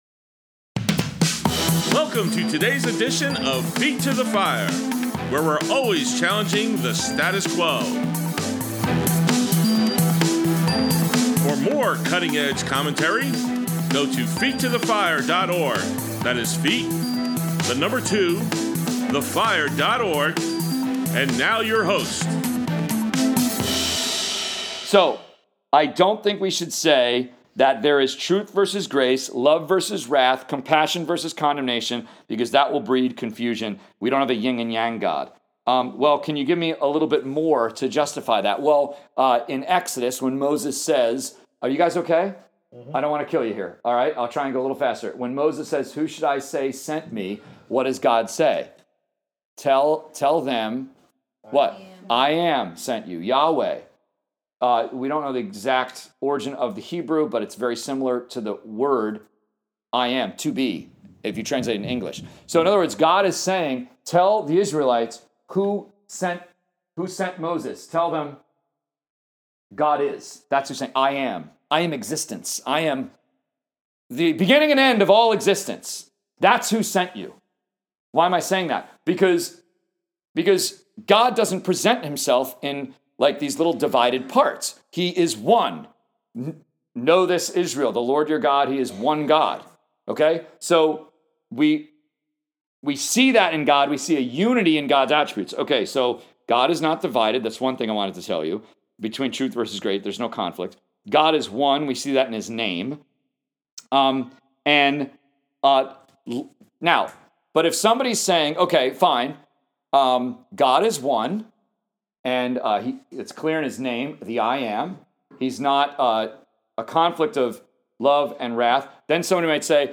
Saturday Sermons 1.17.26 1 Peter 3:8-12 A Biblical Understanding of Compassion, Part 2
College Career Group, Friday, 1.9.26, Part 2